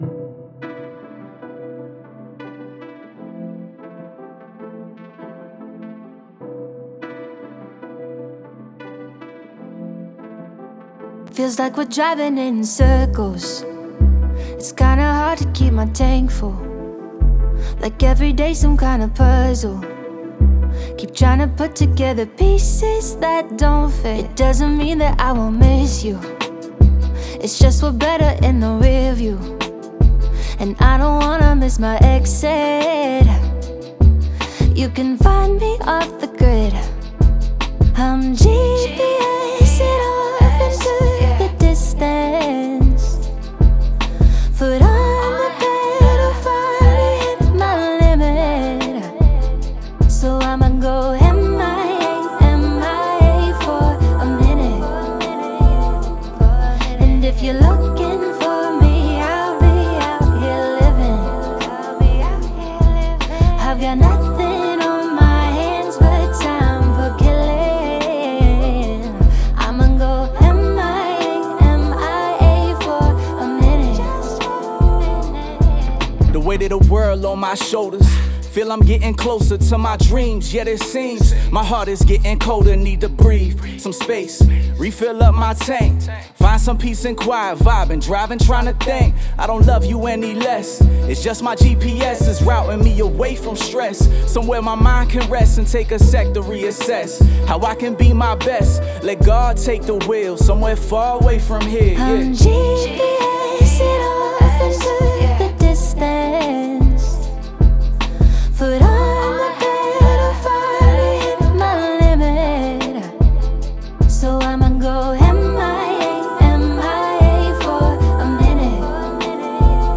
R&B, Hip Hop, Pop
C Maj